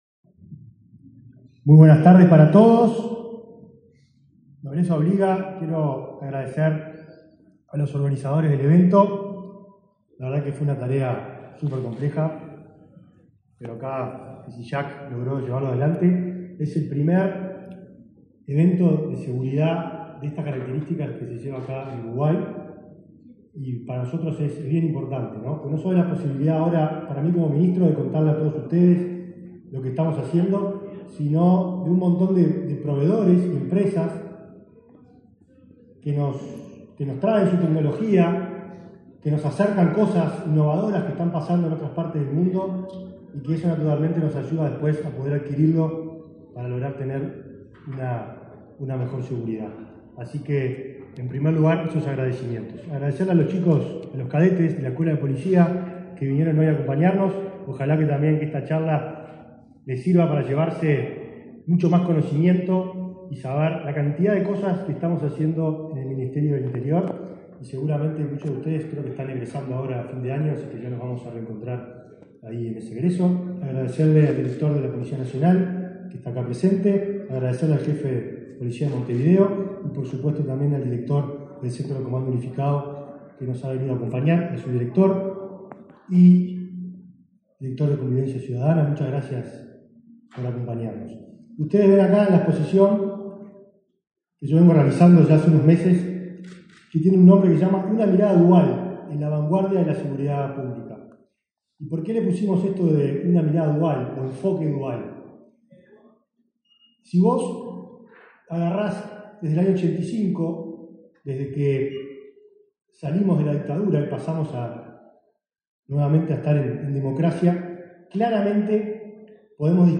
Disertación del ministro del Interior, Nicolás Martinelli
El ministro del Interior, Nicolás Martinelli, disertó, en la Expo Segura, que se desarrolla desde este viernes 4 hasta el domingo 6 en el Laboratorio